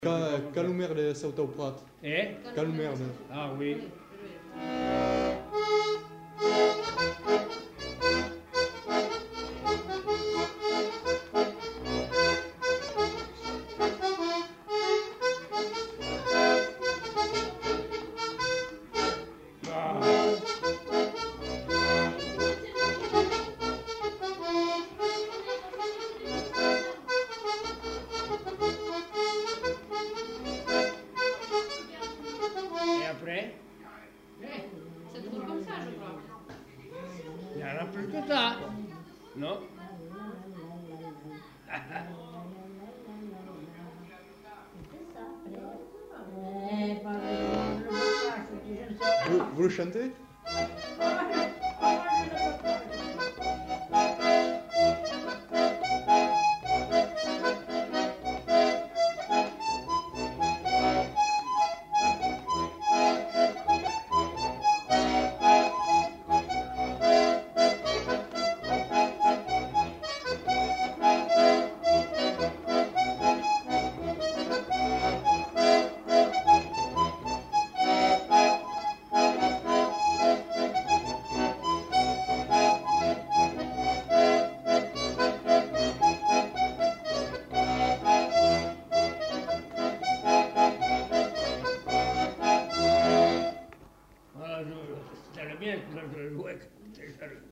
Aire culturelle : Savès
Lieu : Pavie
Genre : morceau instrumental
Instrument de musique : accordéon diatonique
Danse : rondeau
Notes consultables : S'interrompt, puis reprend.